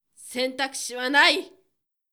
ボイス
ダウンロード 中性_「選択肢はない」
パワフル中性中音